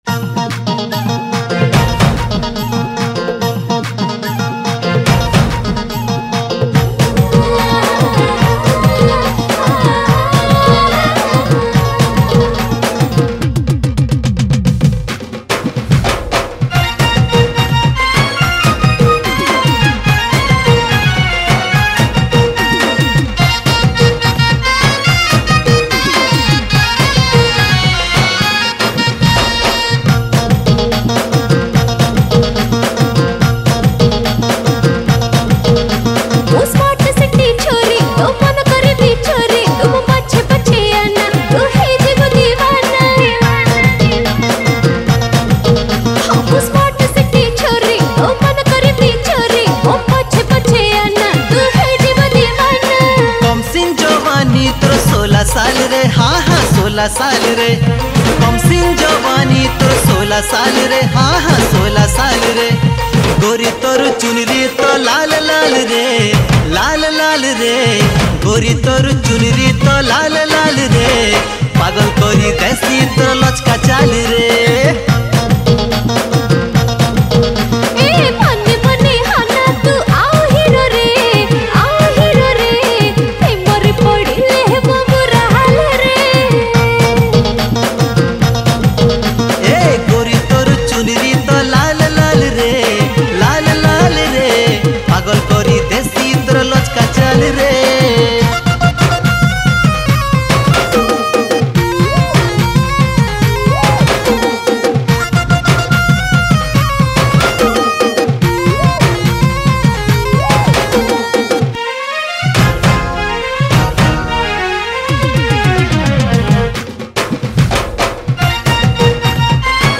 Odia Songs